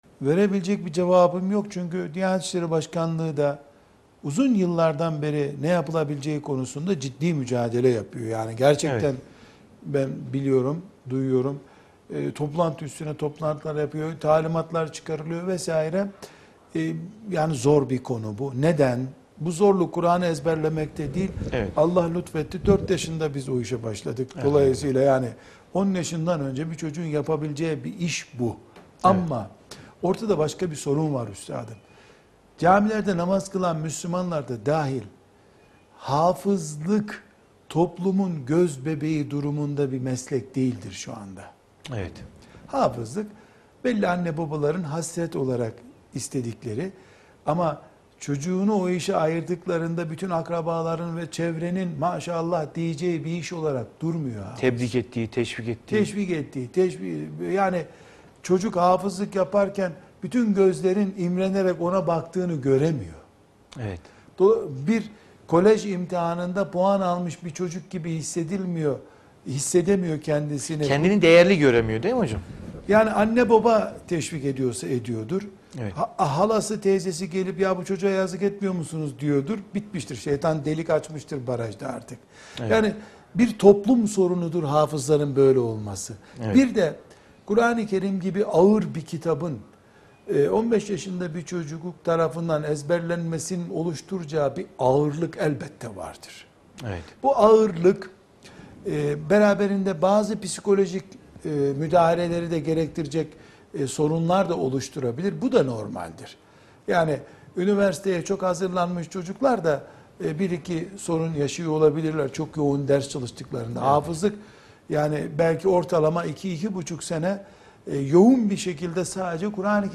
2. Soru & Cevap